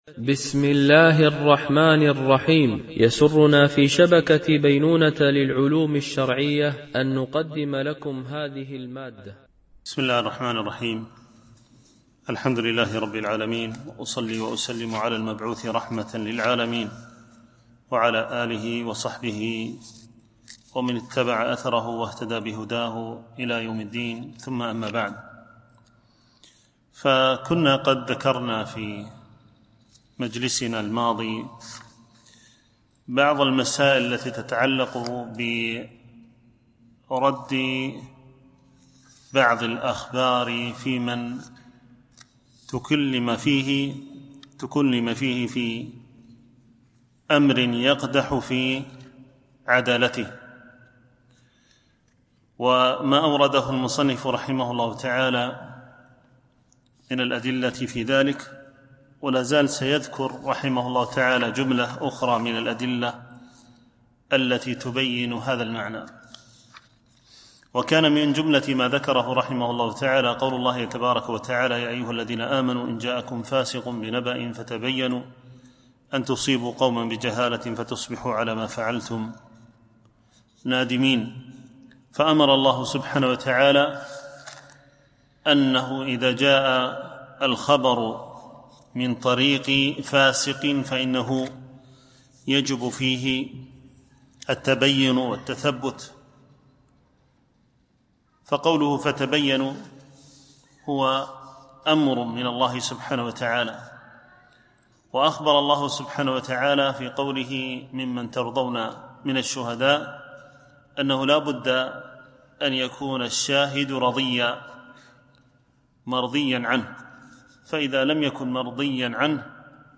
شرح مقدمة صحيح مسلم - الدرس 6